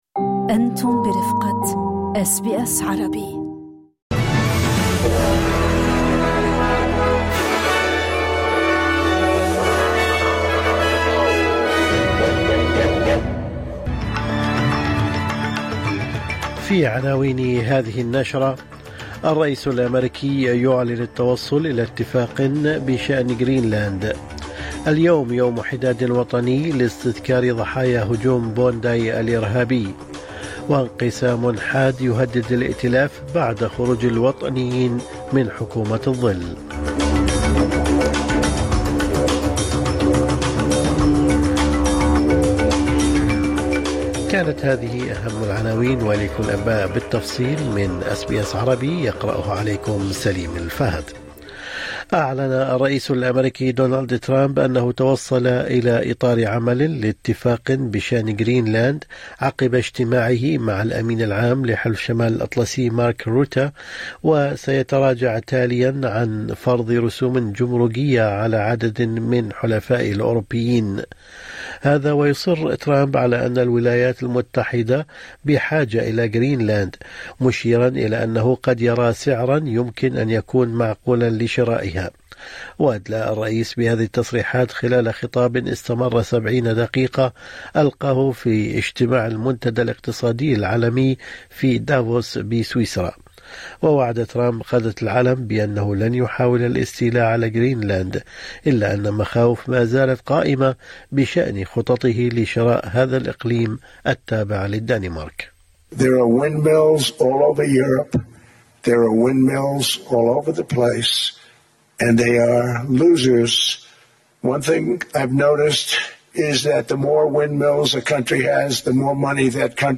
نشرة أخبار الصباح 22/1/2026